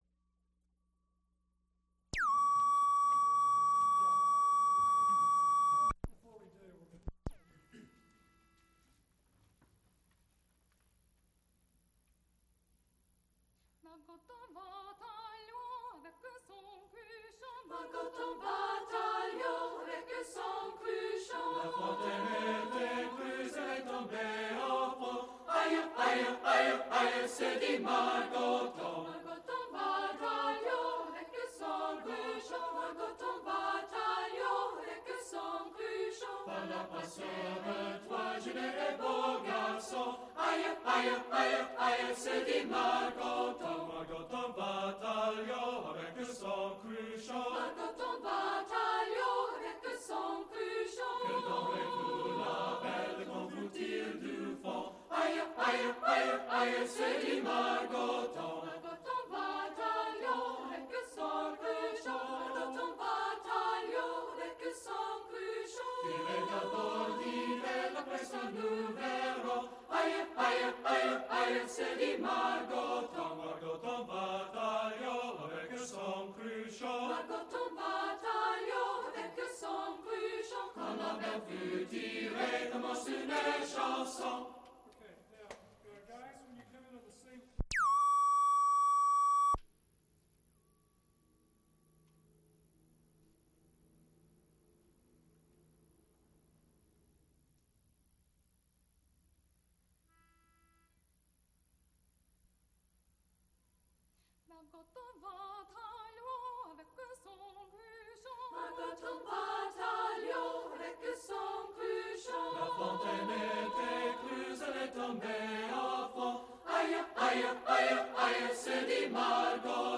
Multiple false starts on each tape.
Recorded live January 9, 1976, Heinz Chapel, University of Pittsburgh.
Extent 3 audiotape reels : analog, half track, stereo, 7 1/2 ips ; 7 in.
Choruses, Sacred (Mixed voices) with orchestra Madrigals Gregorian chants